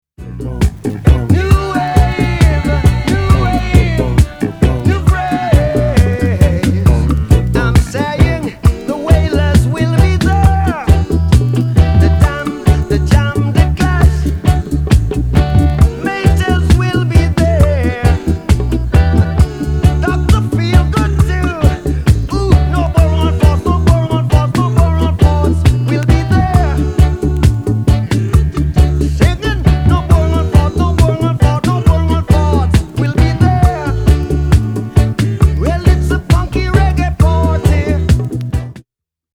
超強力ステッピン・パーティ・チューン！！
嬉しい12インチ・ロング・バージョン！！